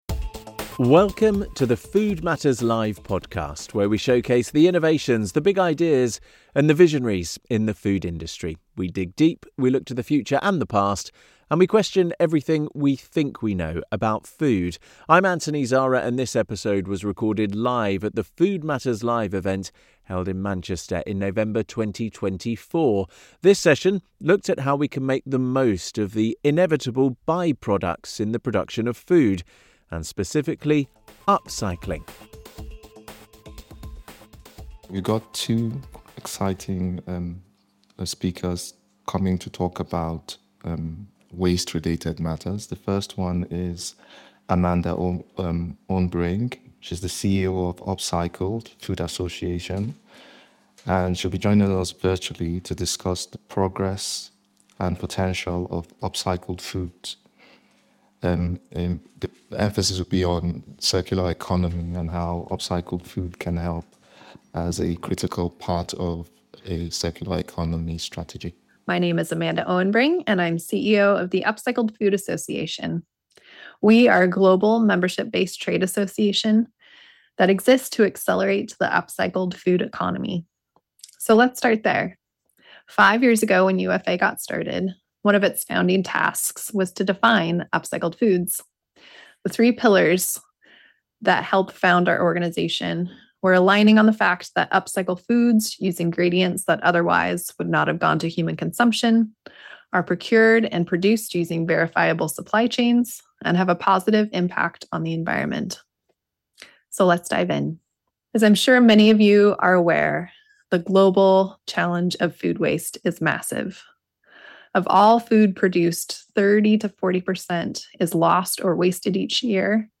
In this episode of the podcast, recorded live at the Food Matters Live event in Manchester in November 2024, we examine the transformative potential of upcycled food, including its economic, environmental, and societal impact.